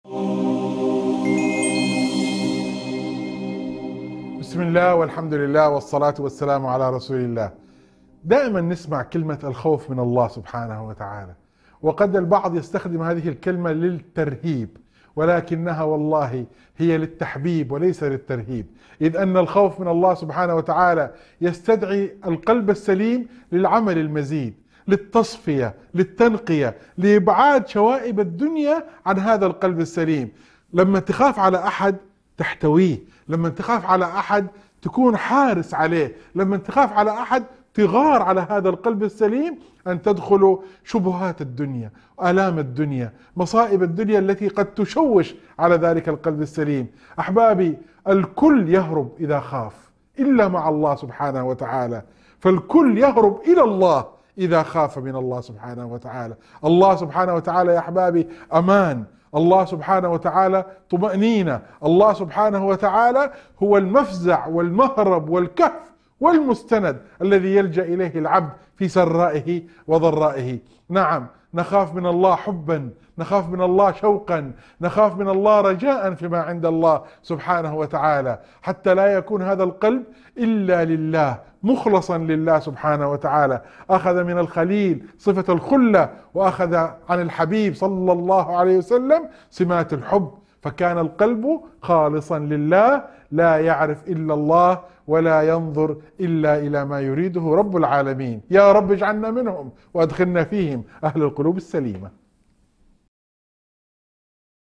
مقطع وعظي يركز على معنى الخوف الحقيقي من الله كونه سبيلاً لتصفية القلب والسعي للنجاة. يشرح كيف يكون الخوف محبةً ووسيلةً للتقرب إلى الله وليس للترهيب، داعياً إلى إخلاص القلب لله تعالى.